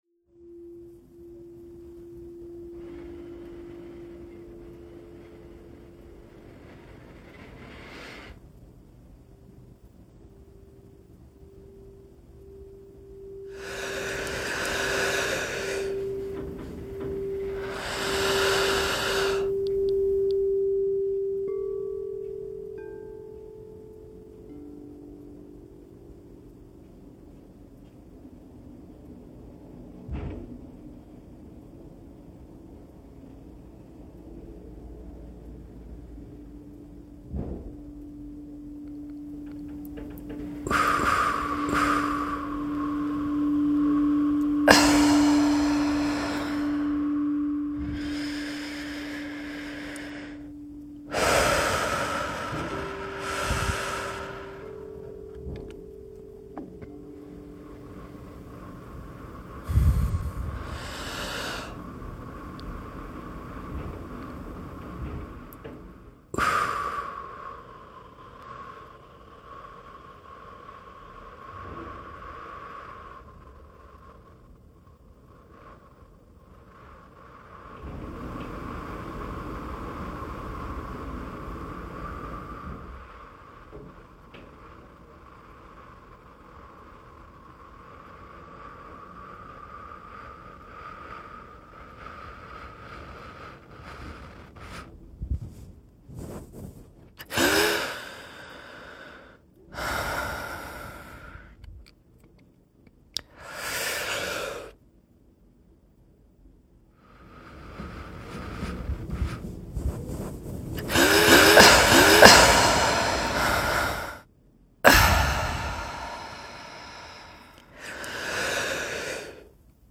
Our homework one week was to create a one-minute piece using field recording. It was supposed to convey an emotionally impactful event from our week…without any talking. For my piece, I tried to convey the feeling of waiting to hear back about something that I really cared about.